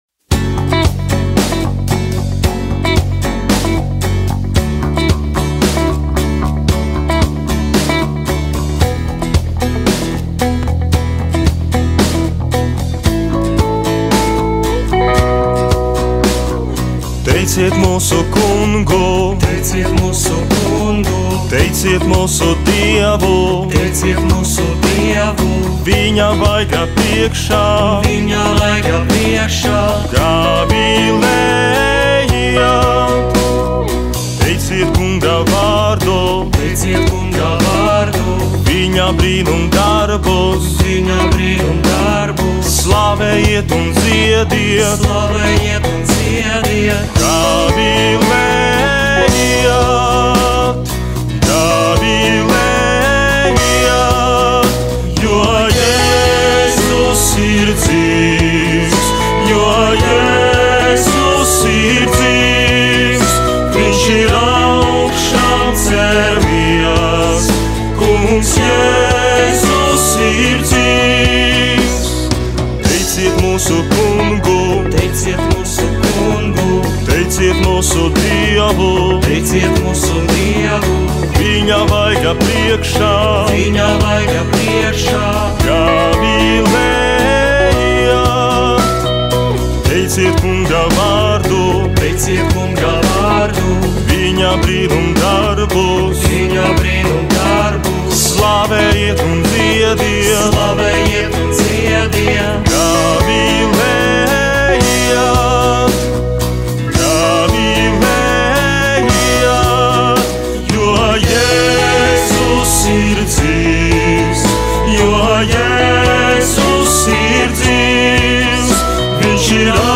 Semināristi ierakstu studijā ieraksta pirmo dziesmu (audio)
klavieres
solo ģitāra
bass
bekvokāls
trompete, vokāls
ritma ģitāra
klarnete, vokāls